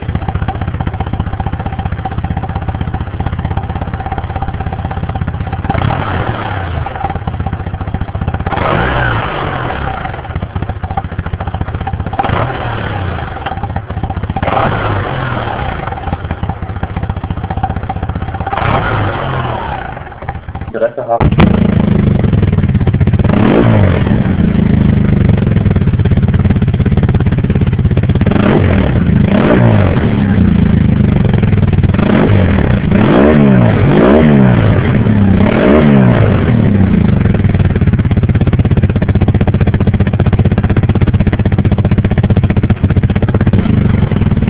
predefinito Scarico r 1200 c
Dal momento che il rumore di scarico è praticamente inesistente vorrei fare qualcosa per incrementare il sound.